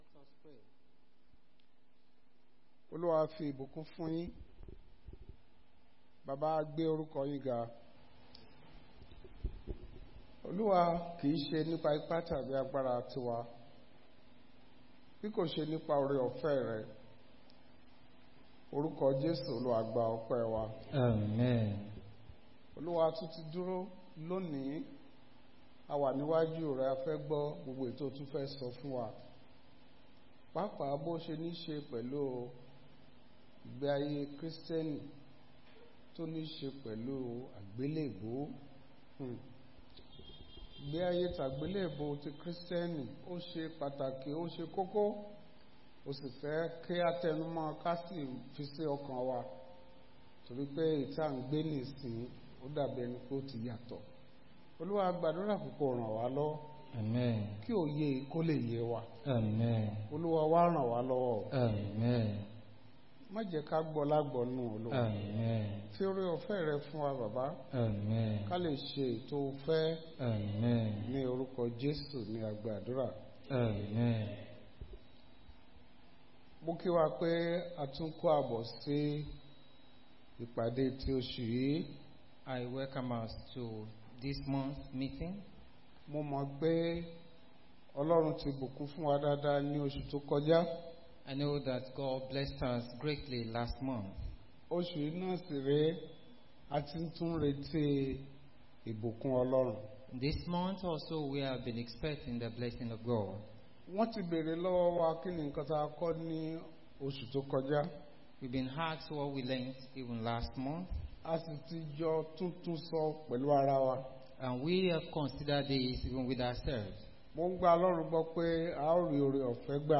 Bible Class Passage: Romans 6:5-10